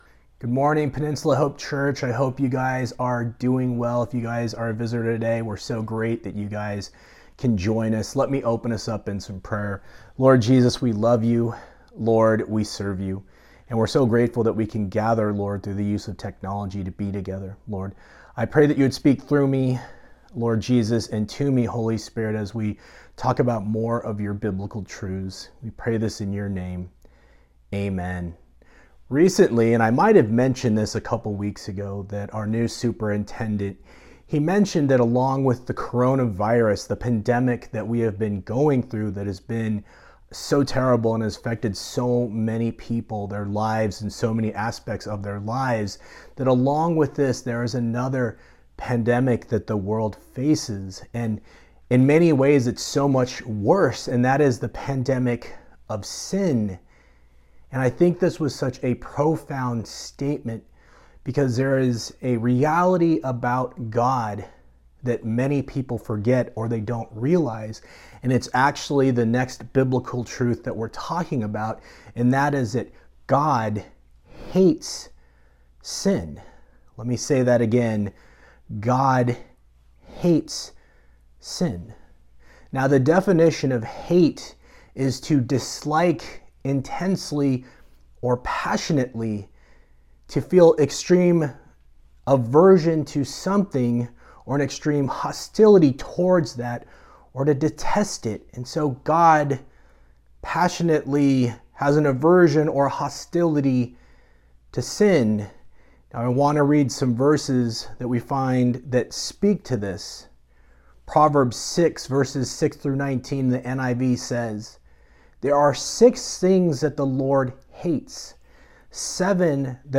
November 1, 2020 Sunday Message